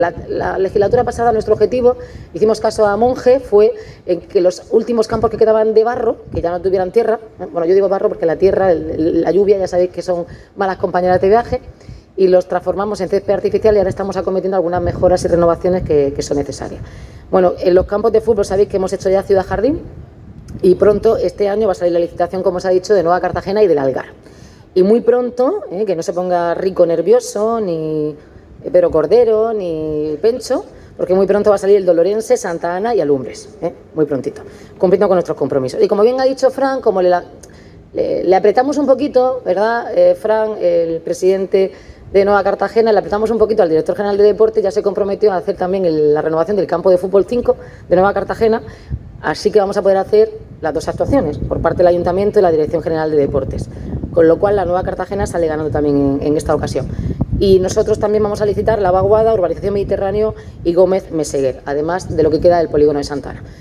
Enlace a Declaraciones de la alcaldesa, Noelia Arroyo.
El puerto de Cartagena ha acogido la puesta de largo del nuevo balón de la Federación de Fútbol de la Región de Murcia (FFRM), que ha celebrado el acto de presentación oficial, en un evento que ha servido como punto de encuentro para una amplia representación del fútbol y el futsal regional.